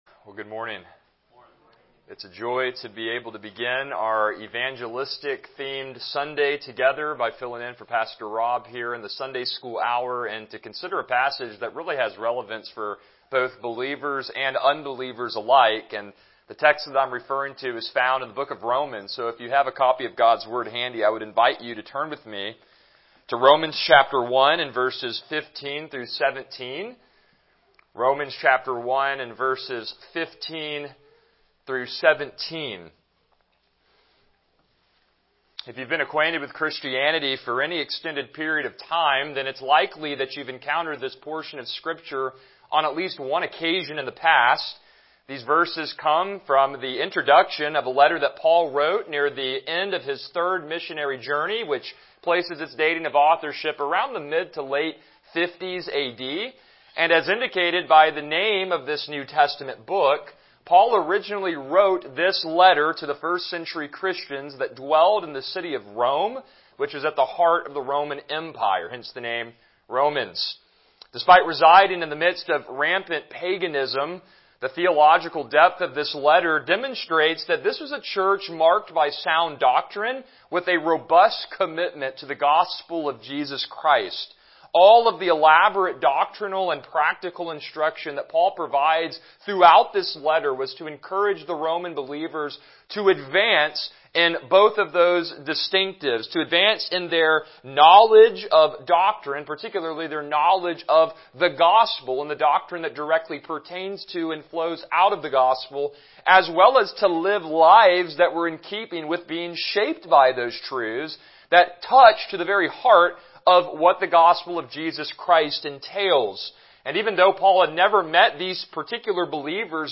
Romans 1:15-17 Service Type: Sunday School « The Calling and Obedience of Abram